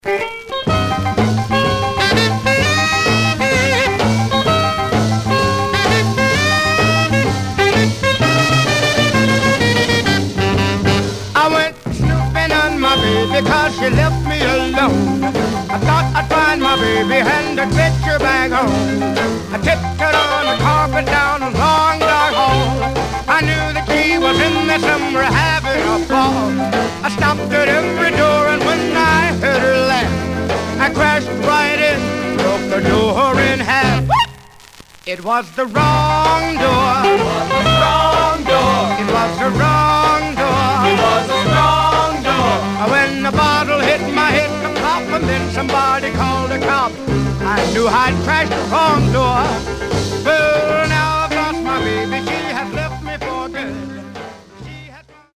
Some surface noise/wear
Mono
Rythm and Blues